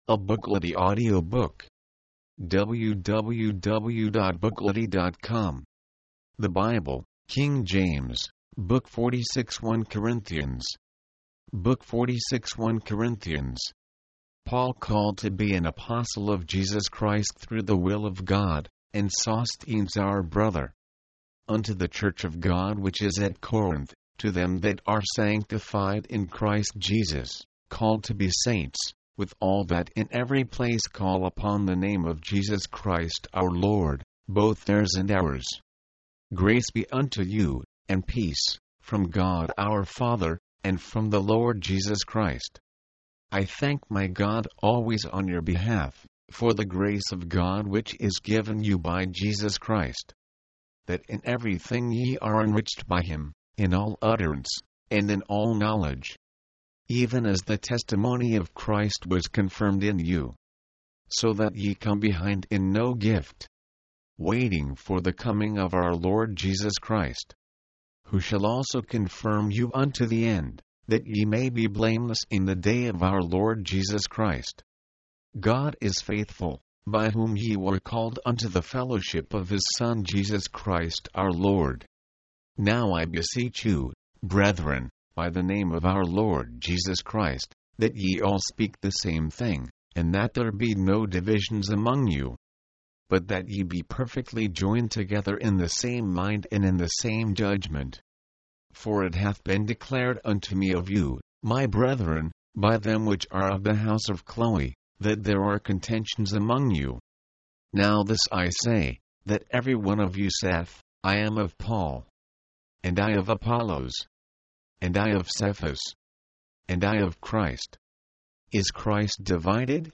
Corinthians 1 Corinthians 1 The Holy Bible: King James Version mp3, audiobook, audio, book Date Added: Dec/31/1969 Rating: 2.